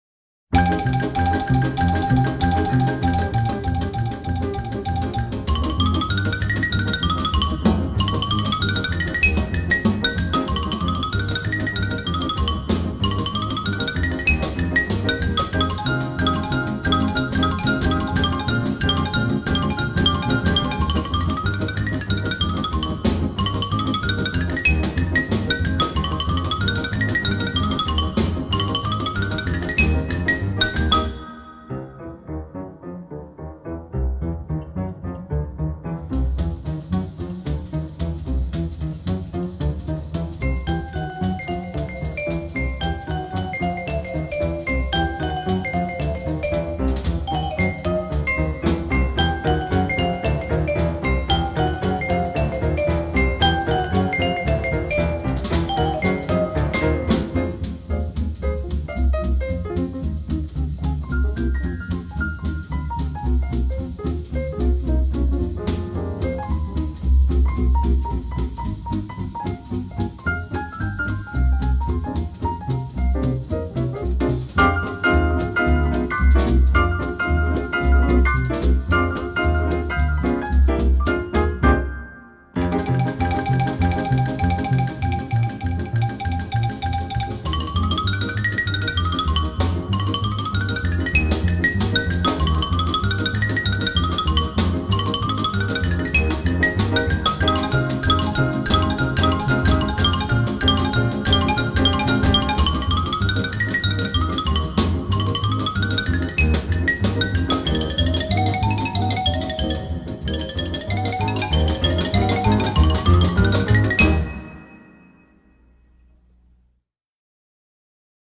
OLED SIIN ▶ muusika ▶ Lounge